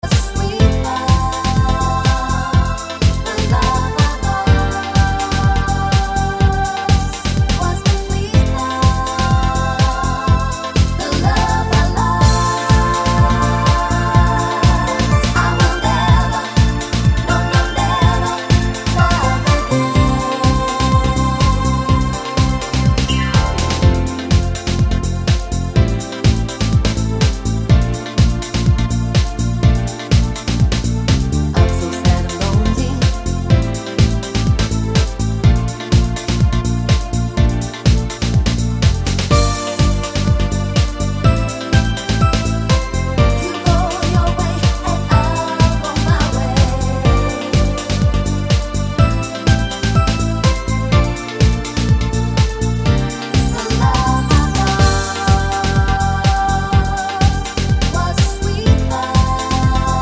Medley Medleys